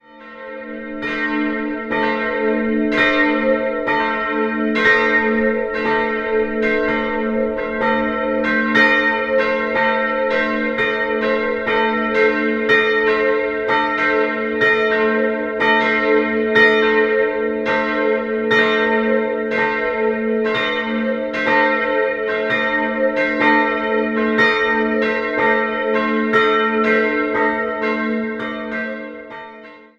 Jahrhundert errichtet. 2-stimmiges Geläute: a'-c'' Die größere Glocke wurde 1950 von Karl Czudnochowsky in Erding gegossen, die kleinere 1871 von Gustav Schröck aus Nürnberg.